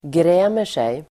Uttal: [gr'ä:mer_sej]